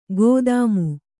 ♪ gōdāmu